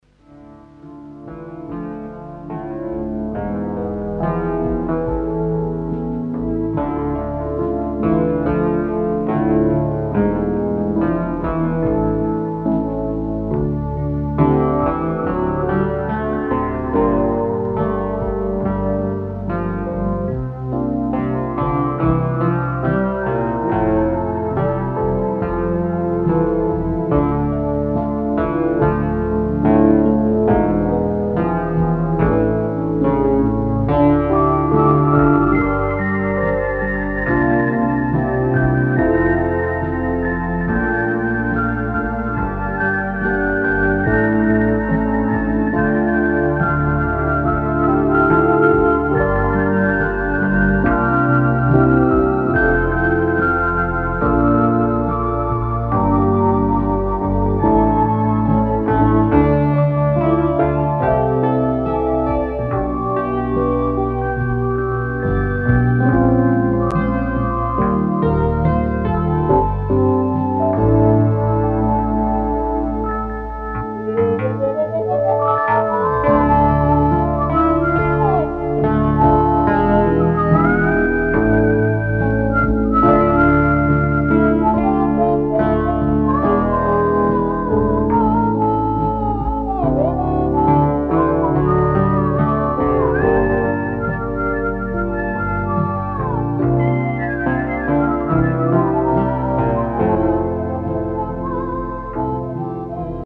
инструментальная пьеса